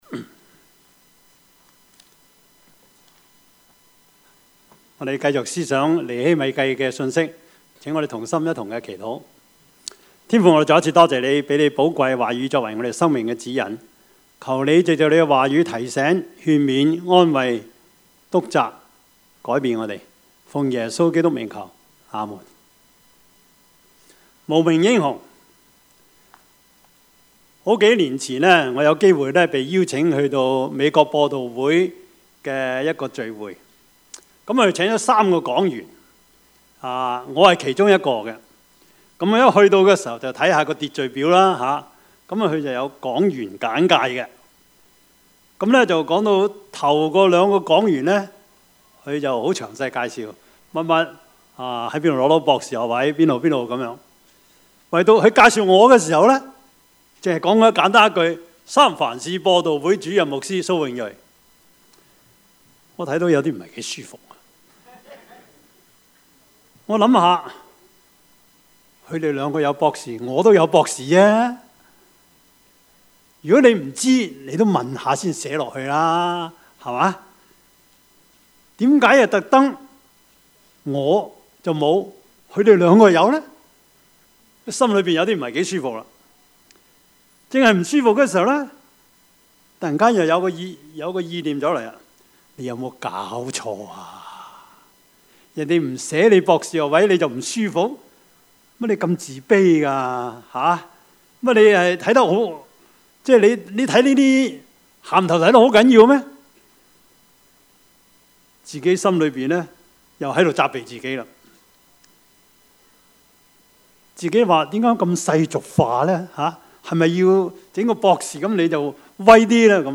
Service Type: 主日崇拜
Topics: 主日證道 « 舟山群島戰役 蔡孝乾 »